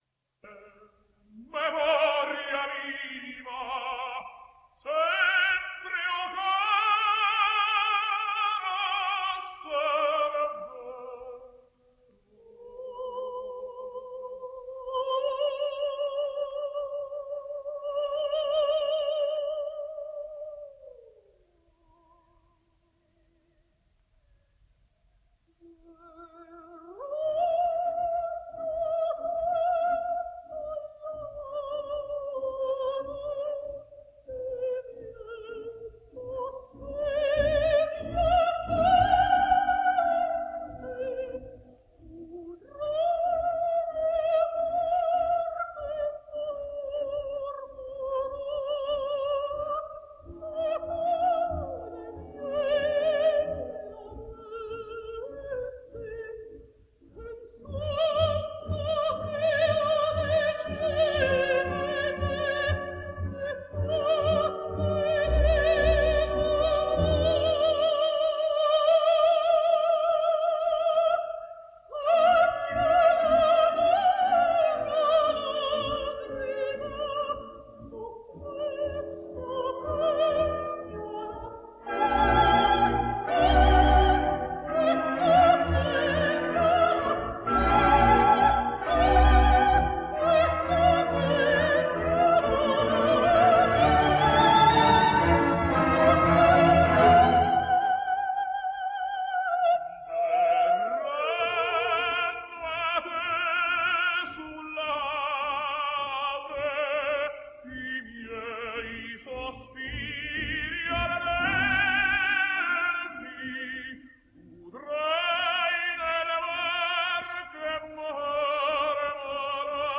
Italian tenor.